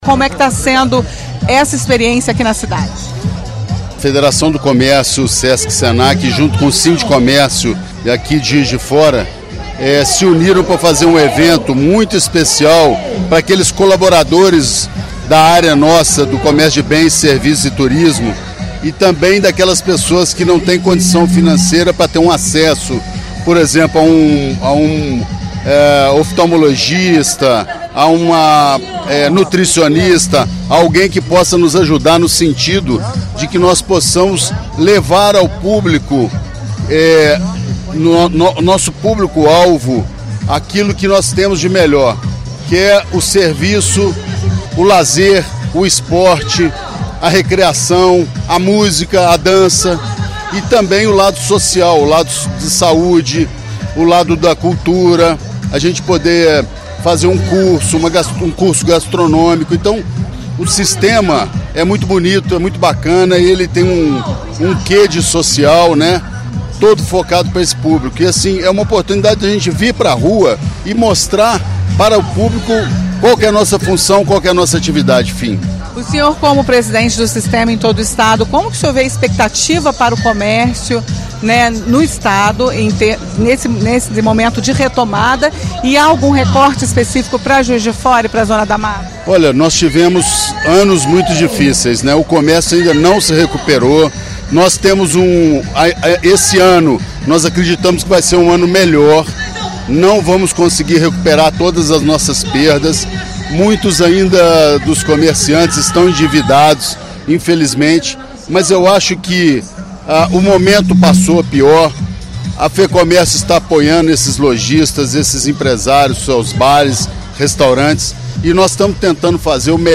conversou com a Itatiaia, no local do evento.